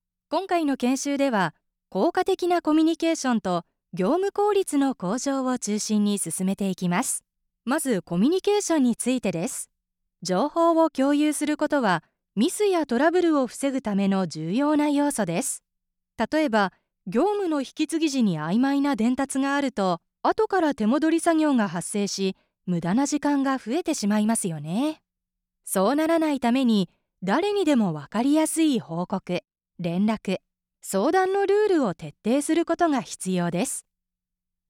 元CATVアナウンサーが温かみのある爽やかな声をお届けします。
社員教育動画